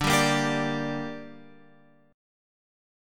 D Suspended 4th Sharp 5th